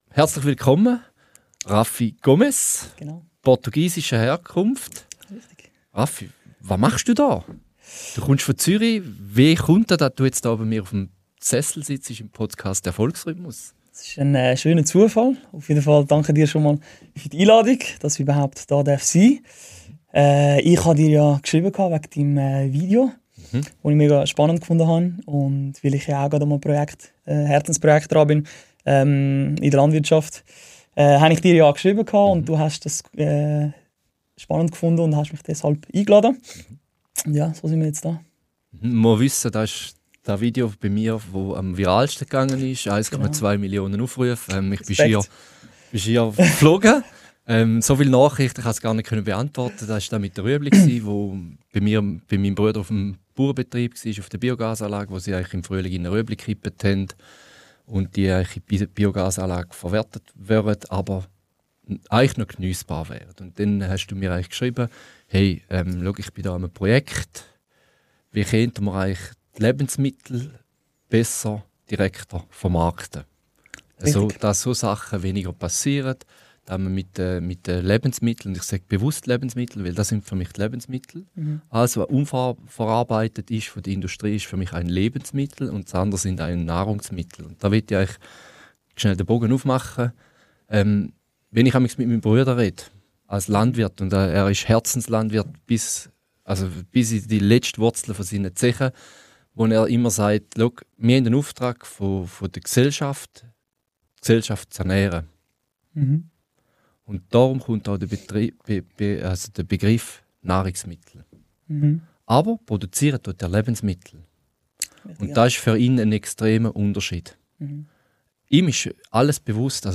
Ein ehrliches Gespräch über Naivität als Superkraft, den inneren Drang, der nicht aufhört, und die Frage, wie wir als Gesellschaft wieder näher an unsere Lebensmittel und an die Natur finden.